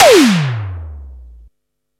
SIMMONS SDS7 4.wav